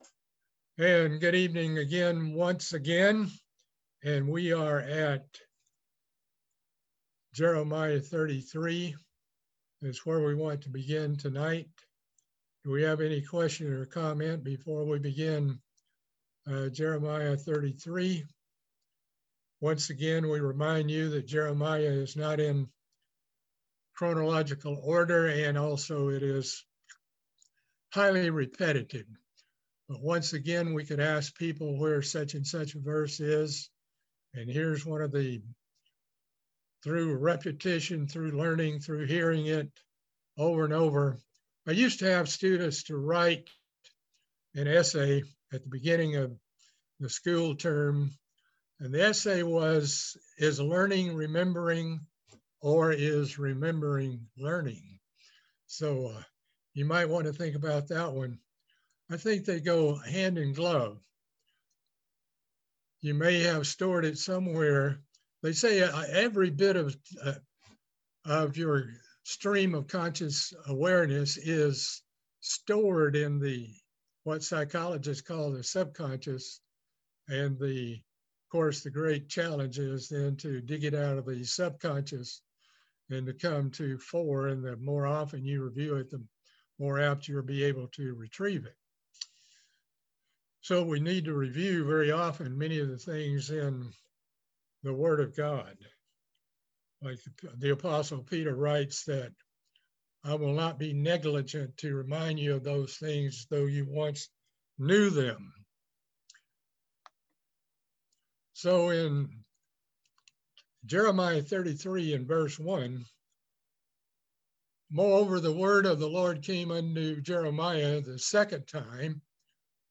Bible study series on the book of Jeremiah - Part 19